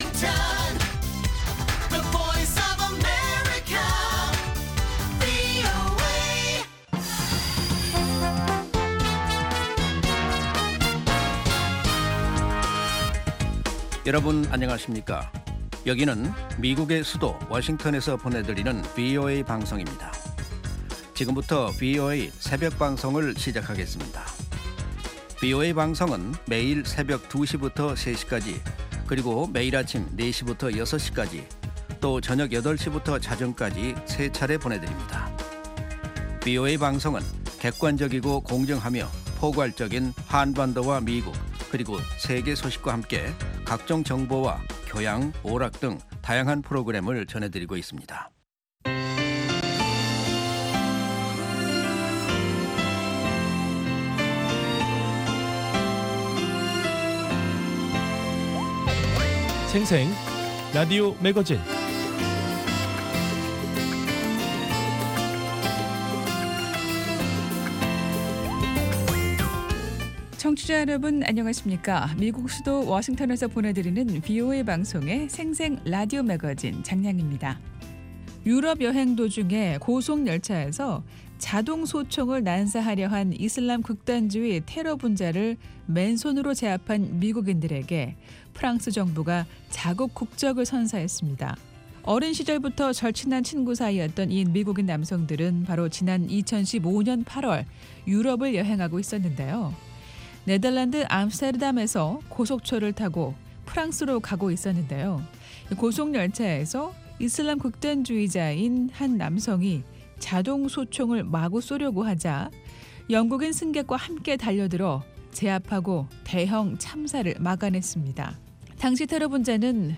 VOA 한국어 방송의 일요일 새벽 방송입니다.